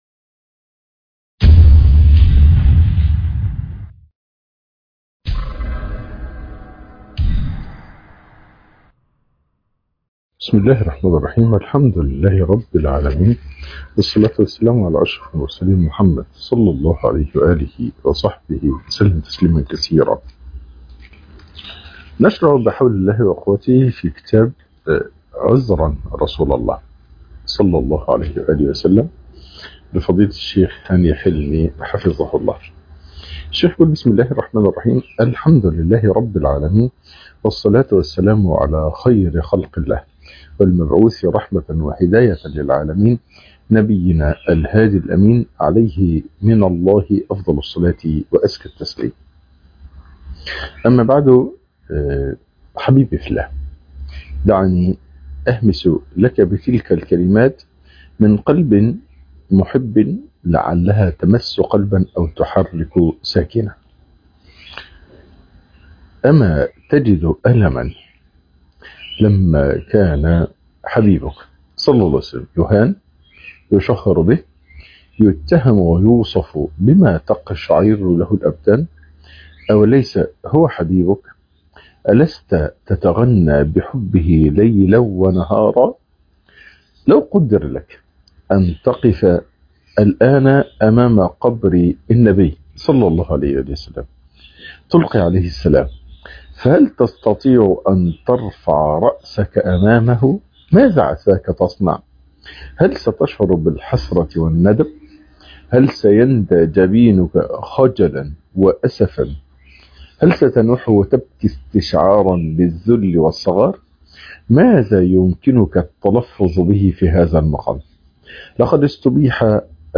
شرح كتاب عذرآ رسول الله - الدرس الاول